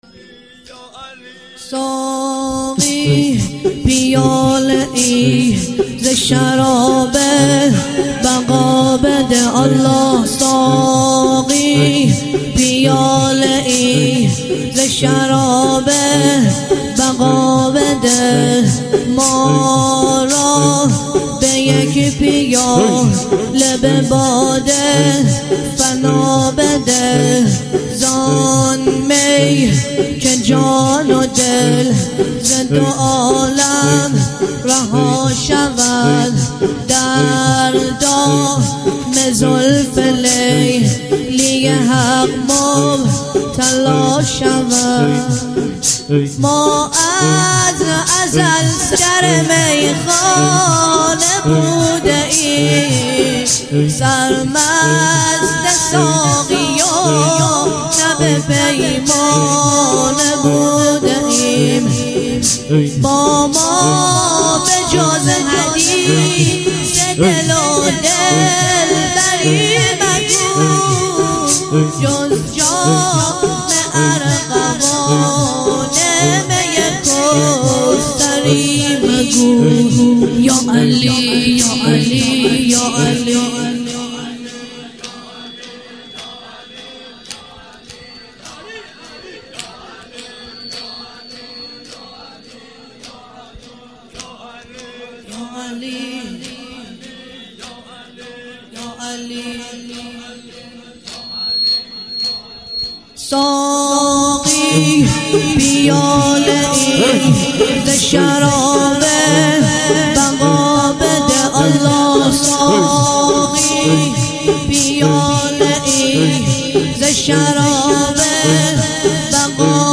تک - ساقی پیاله ز شراب بقا بده